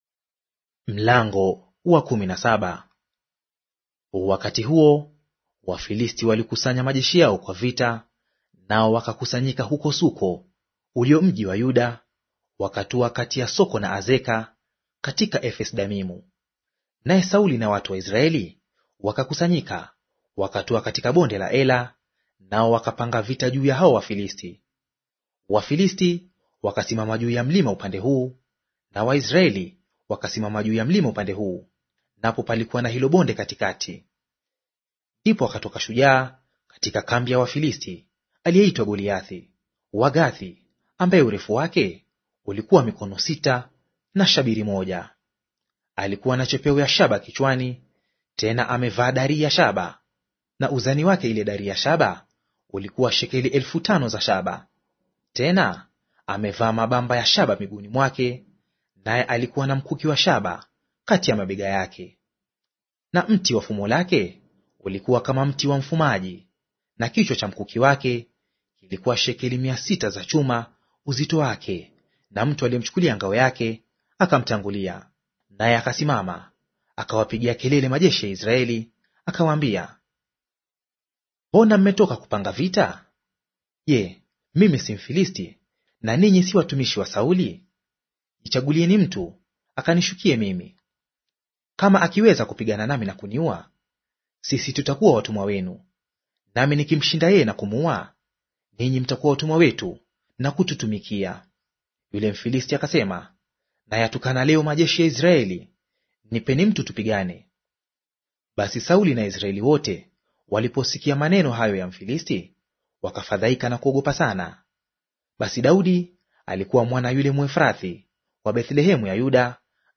Audio reading of 1 Samweli Chapter 17 in Swahili